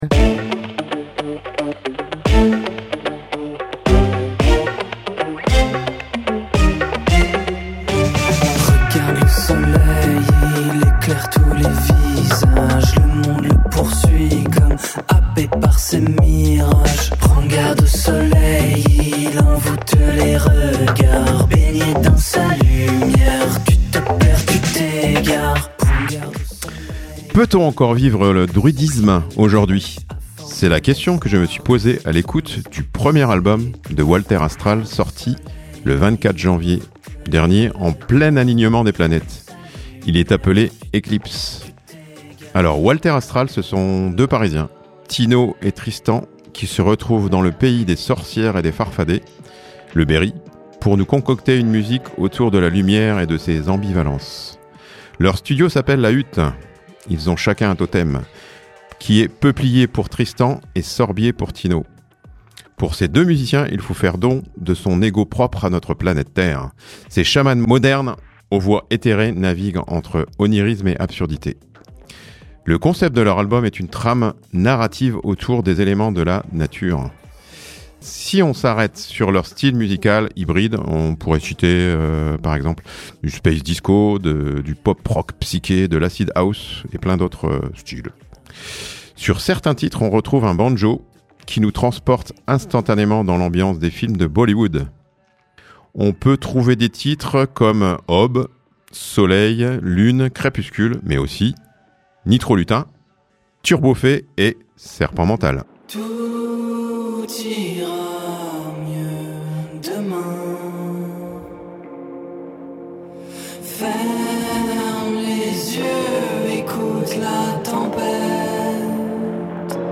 Chronique (8:36)